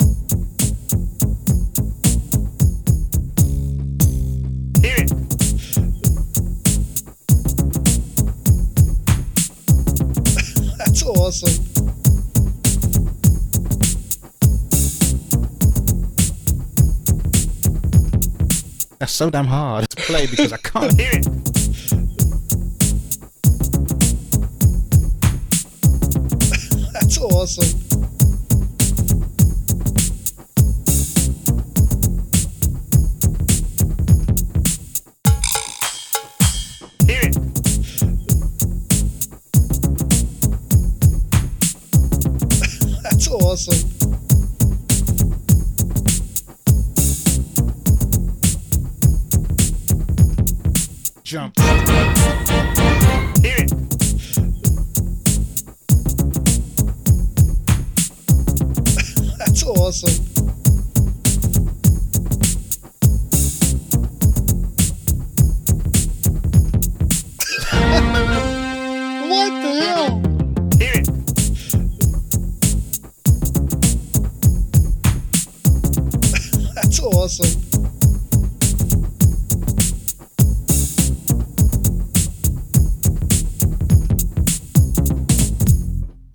I also made a quick remix/song from one bit which is pretty cool.
Filed under: Audio / Music, Original Recording, Synthesizers